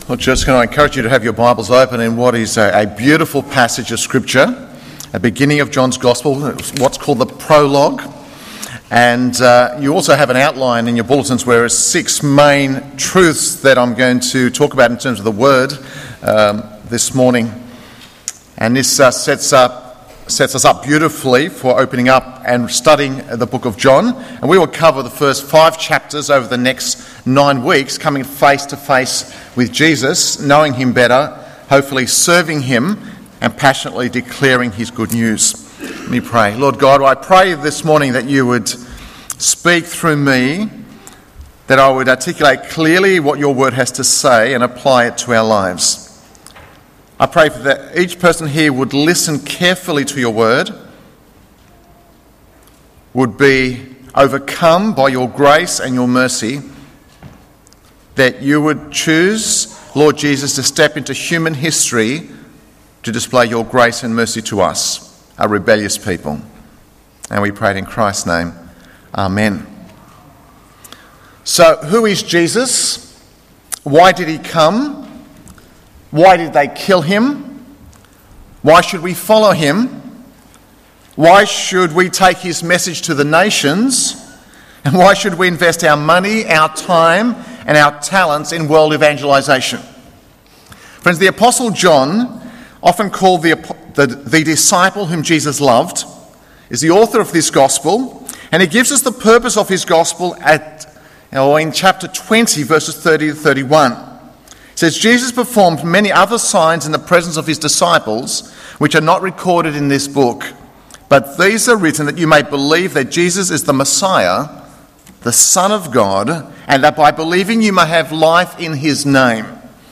Narwee Baptist Church - Sermon Archive